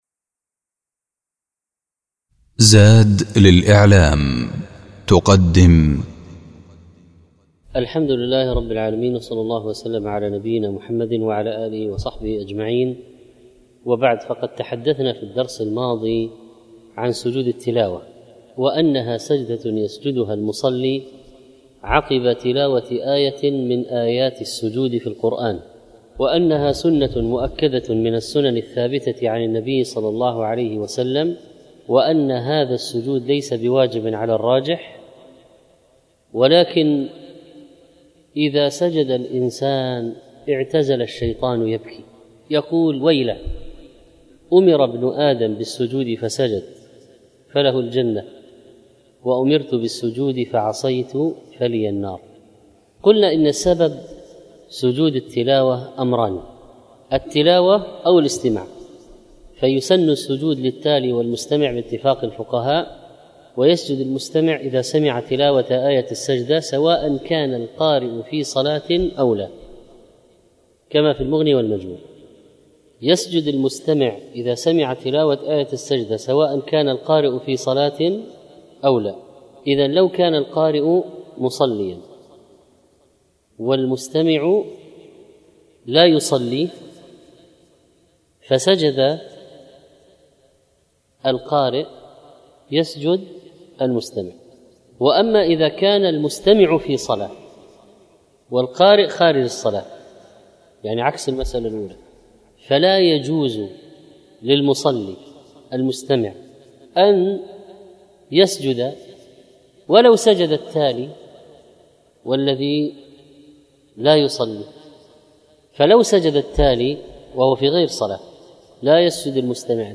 سجود التلاوة - الدرس الثاني